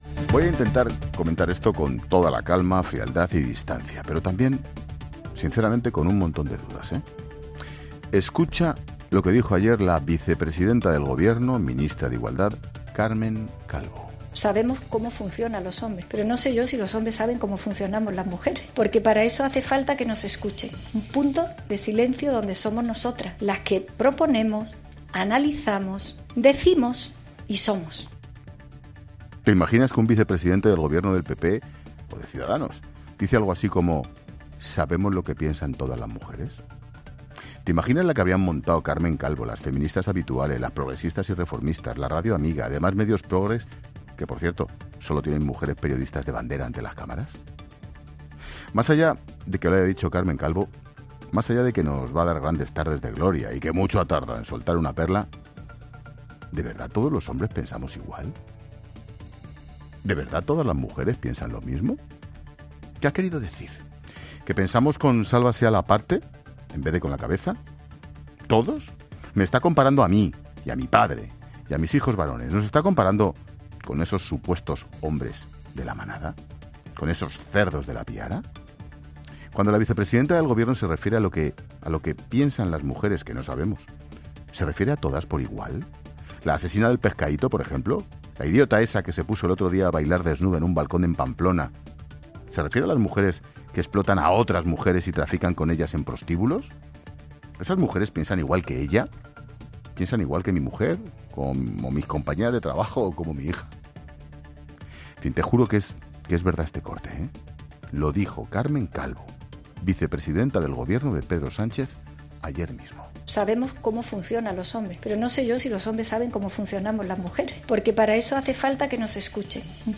Monólogo de Expósito
Escucha el monólogo de Ángel Expósito a las 18h